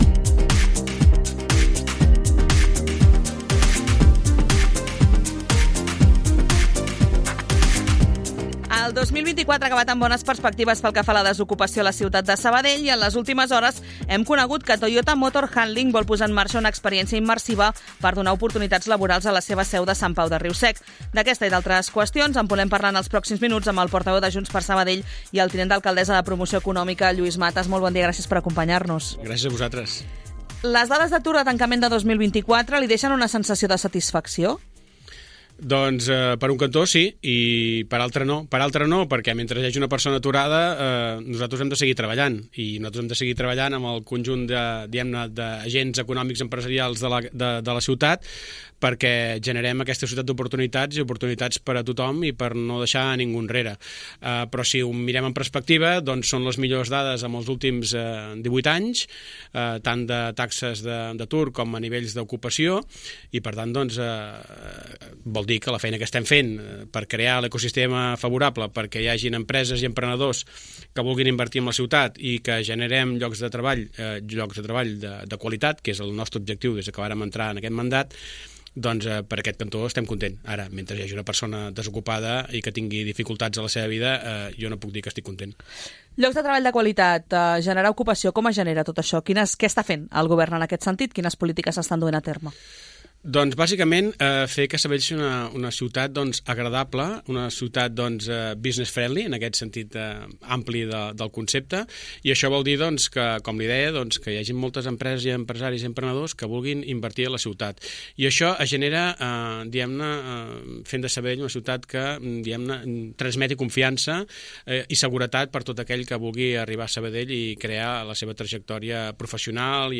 El tinent d’alcaldessa de Promoció Econòmica, Lluís Matas, ha passat avui pels Fils de Ciutat RàdioSabadell per passar revista a l’actualitat de la ciutat.